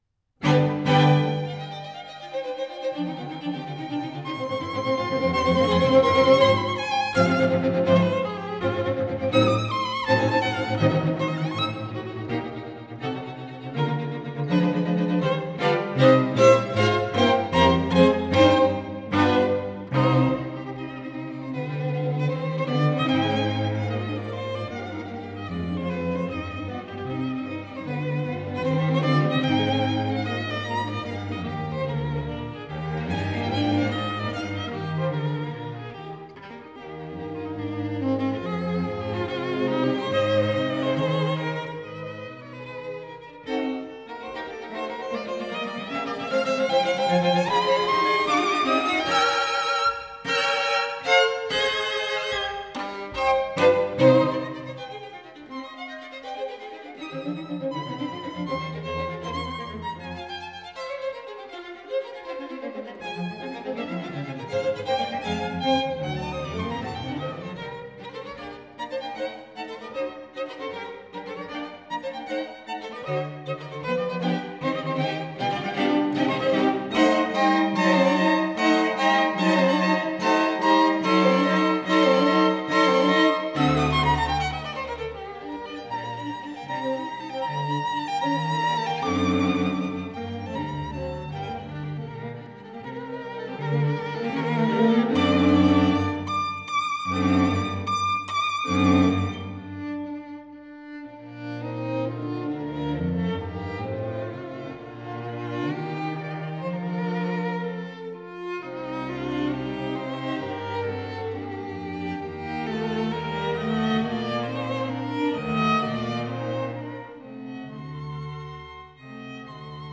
(04) [Felix Mendelssohn Bartholdy] String Quartet in E flat major Op. 12 - Molto allegro e vivace.flac